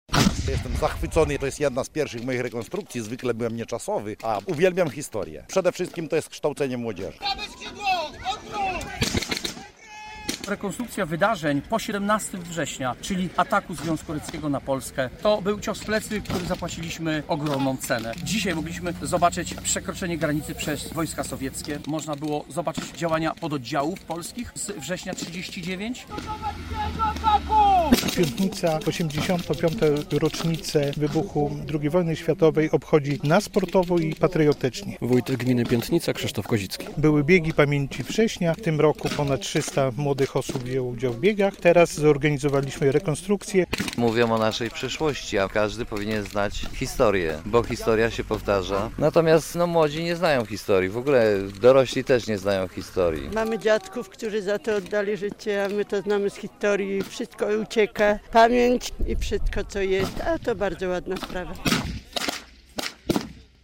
W Piątnicy oddają hołd bohaterom II wojny światowej - relacja